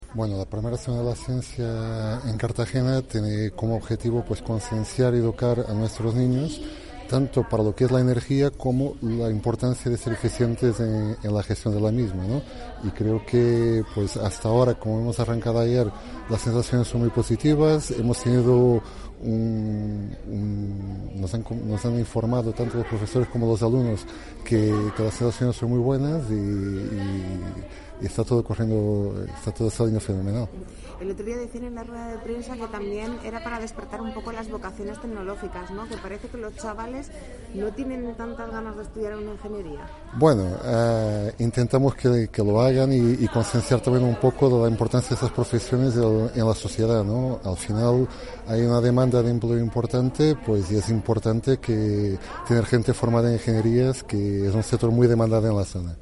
Audio: Declaraciones de David Mart�nez en la Semana de la Ciencia (MP3 - 324,95 KB)